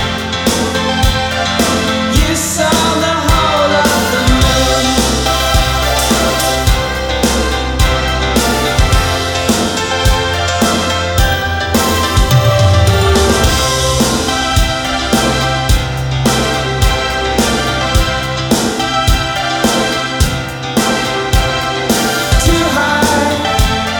no Backing Vocals Irish 4:59 Buy £1.50